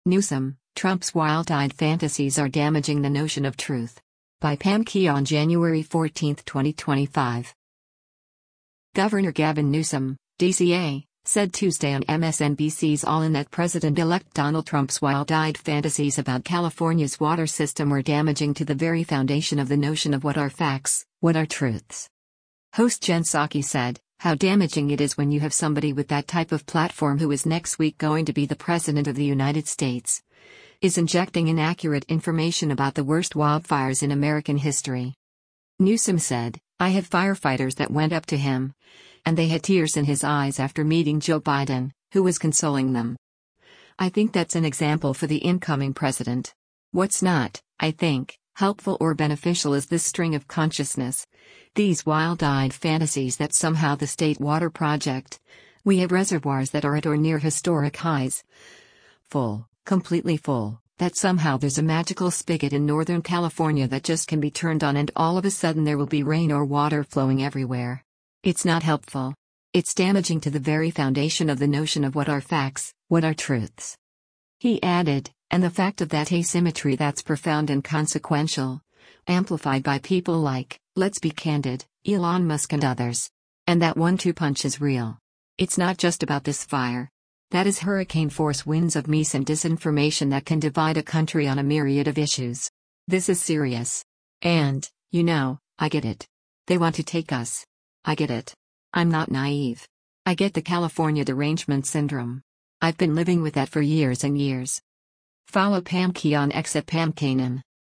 Governor Gavin Newsom (D-CA) said Tuesday on MSNBC’s “All In” that President-elect Donald Trump’s “wild-eyed fantasies” about California’s water system were “damaging to the very foundation of the notion of what are facts, what are truths.”